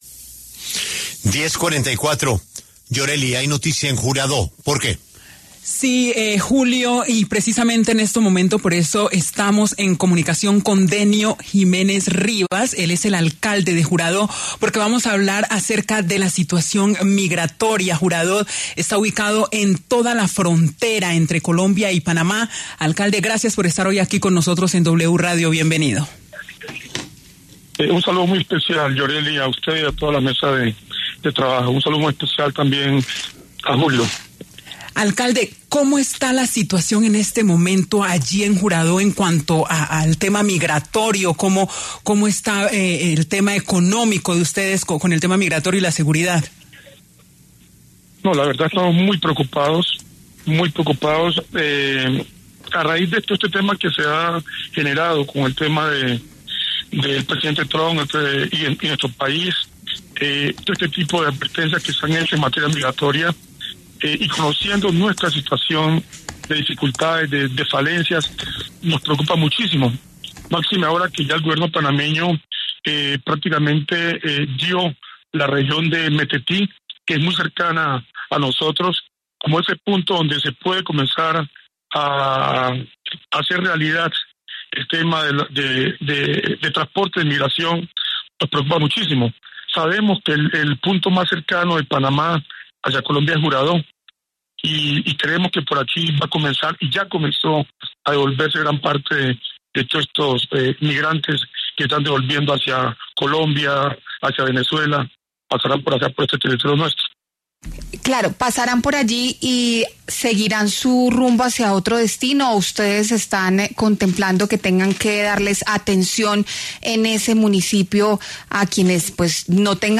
Denio Jiménez Rivas, alcalde de Juradó, conversó con La W a propósito de la crisis que se desataría por el regreso de miles de migrantes por El Darién antes las medidas impuestas por Donald Trump en Estados Unidos.